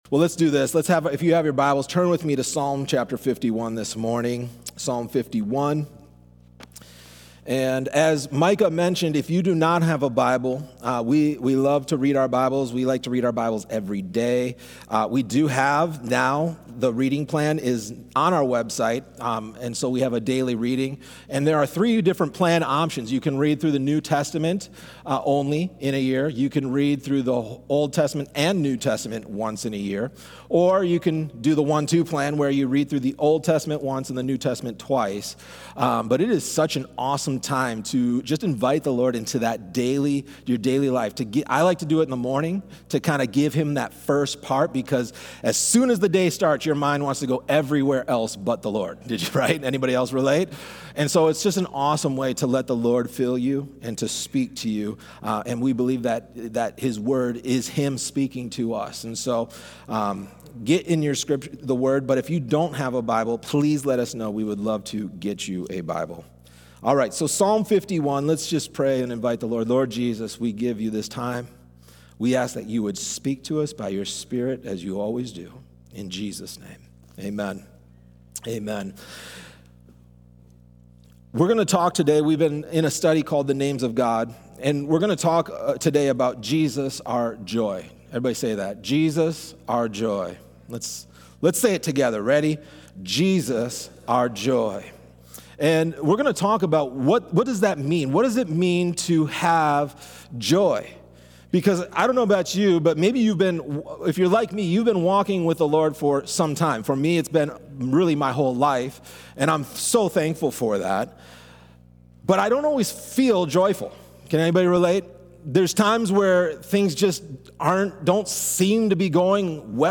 Questions This Sermon Answers 1.